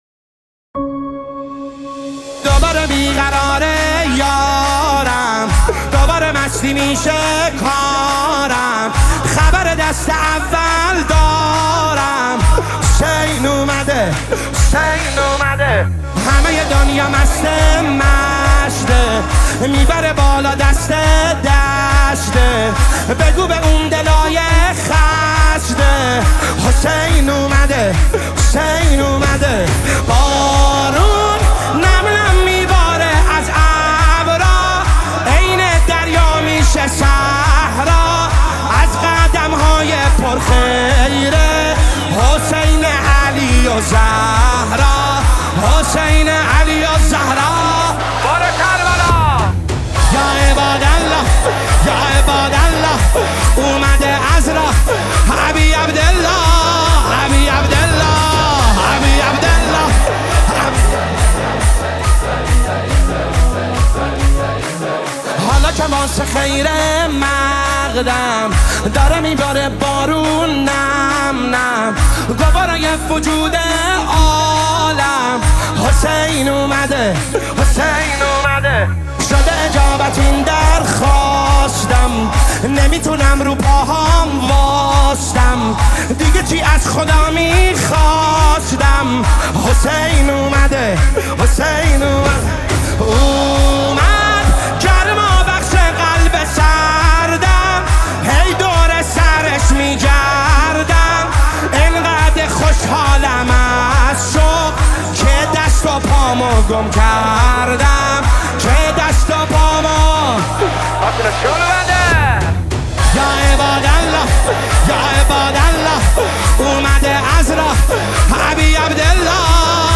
نماهنگ استودیویی سرود مولودی